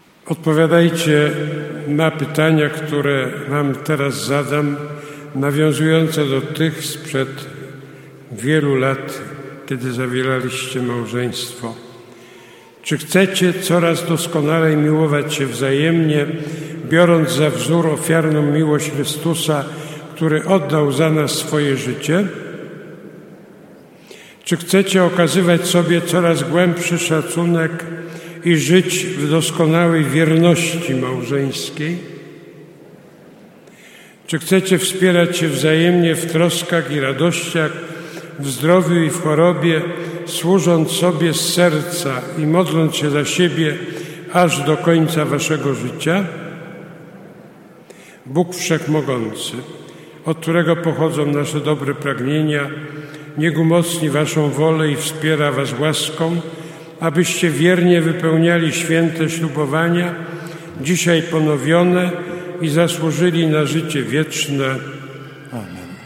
Po odśpiewaniu przez wiernych hymnu do Ducha Świętego małżonkowie odnowili swe przyrzeczenia małżeńskie, nawiązujące do słów, wypowiadanych w trakcie ślubu.
Msza-za-dar-rodziny-2021-Odnowienie-przyrzeczen-malzenskich.mp3